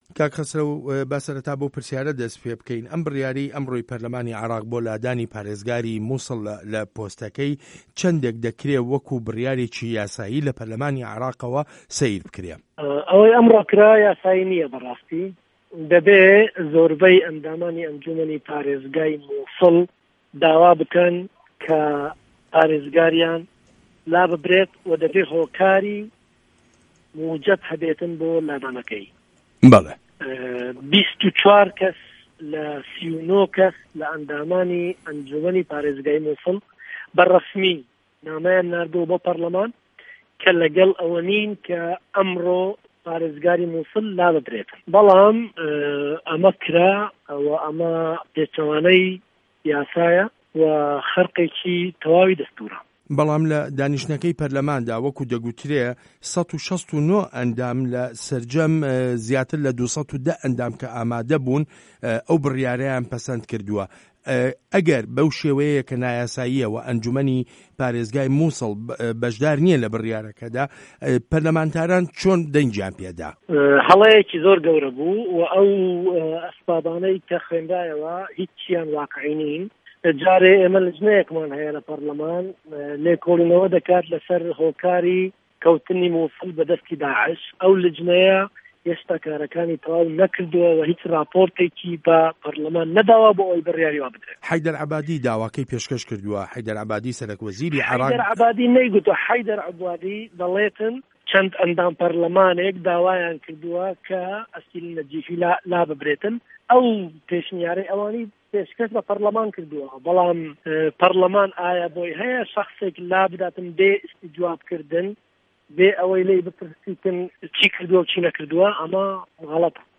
وتووێژ له‌گه‌ڵ خه‌سره‌و گۆران